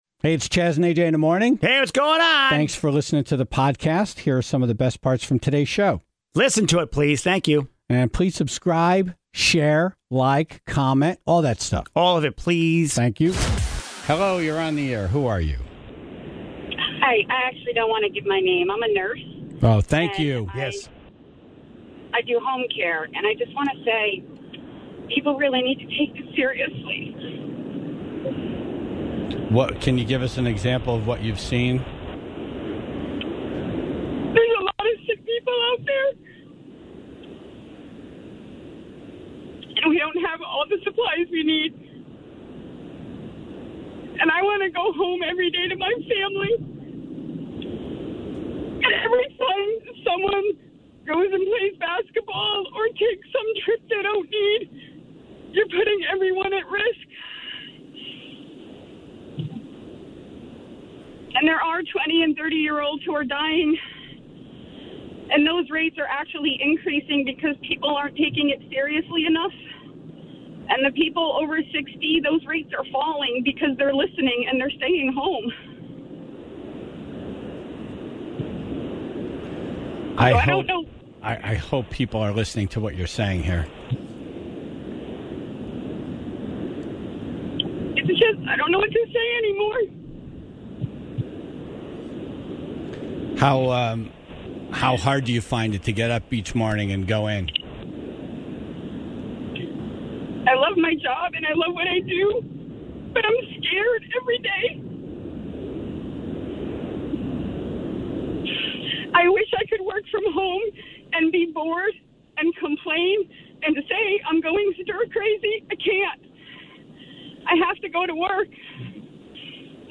An anonymous nurse calls in from the road, on her way to work, and emotionally pleads with the public to stay home, stop playing basketball or trying to organize a vacation trip (0:00)